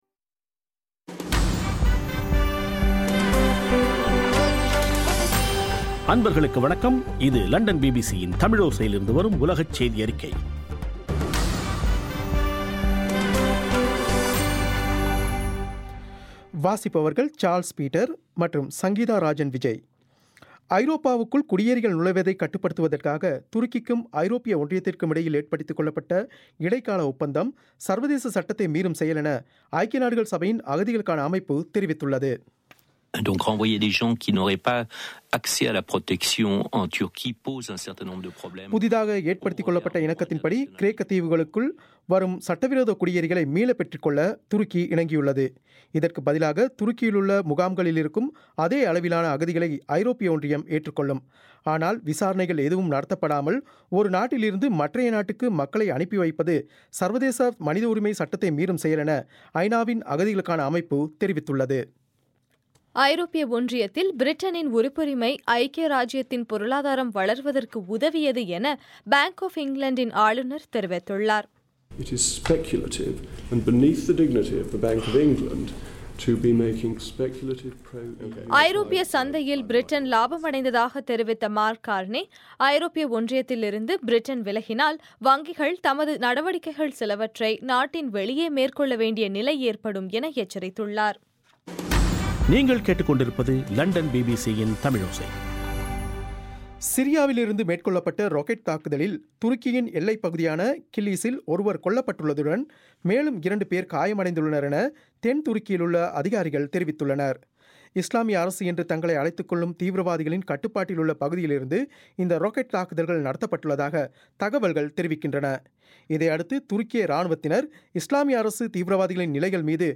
மார்ச் 8, 2016 பிபிசி தமிழோசையின் உலகச் செய்திகள்